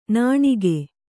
♪ nāṇige